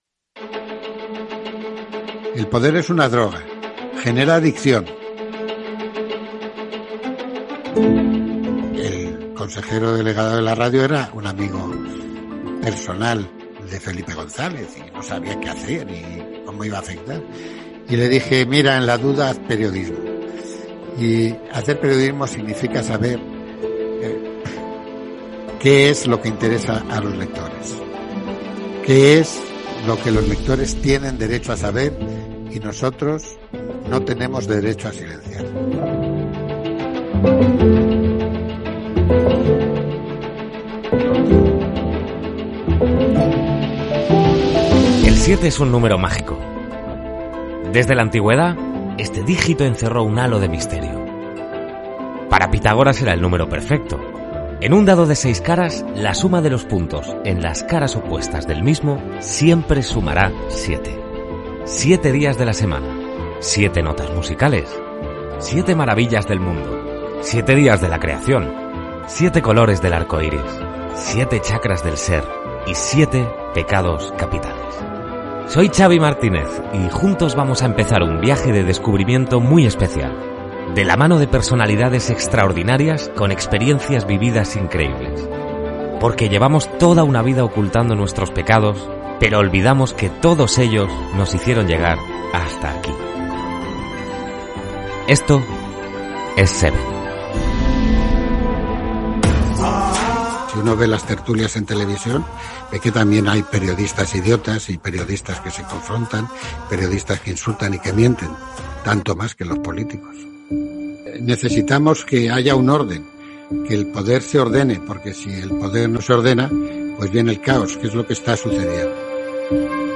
Paraules de Juan Luis Cebrián, el número set, entrevista a l' exdirector del diari "El País", Juan Luis Cebrián